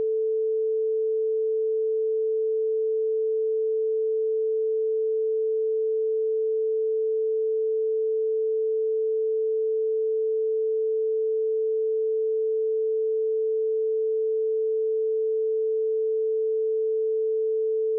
sample-lesson.mp3